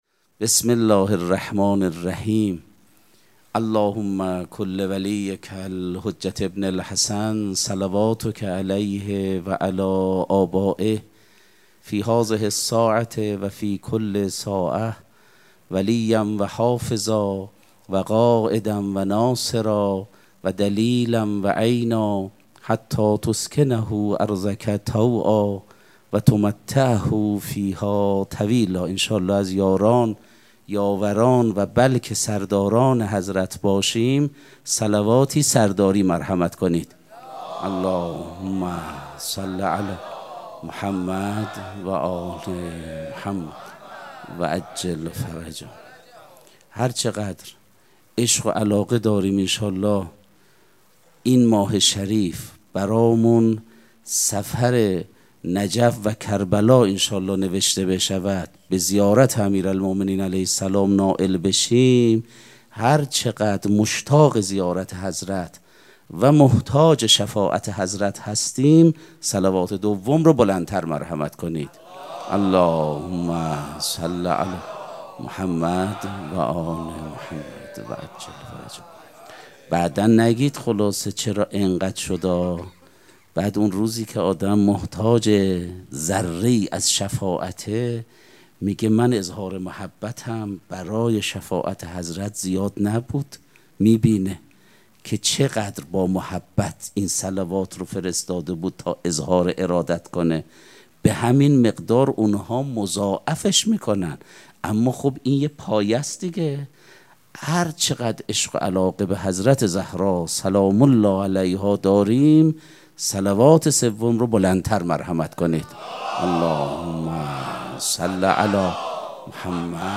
سخنرانی
مراسم مناجات شب هفدهم ماه مبارک رمضان دوشنبه ۲۷ اسفند ماه ۱۴۰۳ | ۱۶ رمضان ۱۴۴۶ حسینیه ریحانه الحسین سلام الله علیها